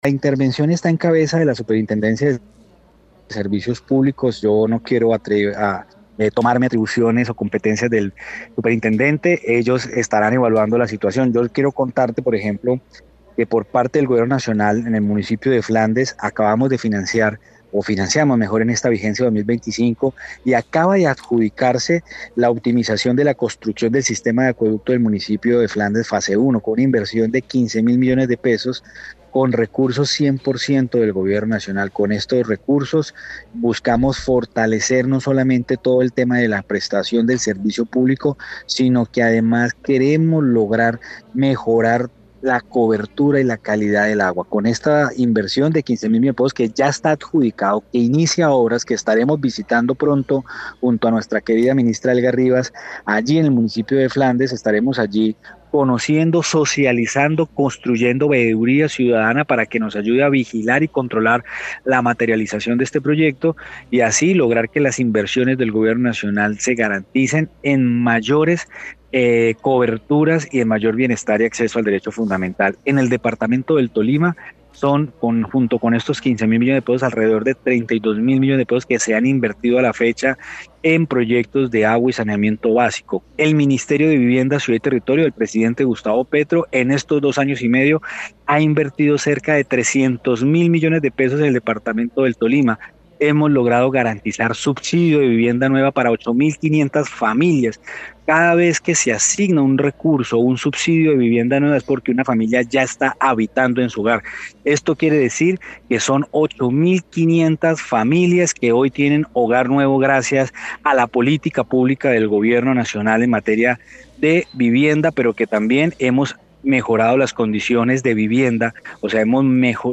Edward Libreros, Viceministro de Agua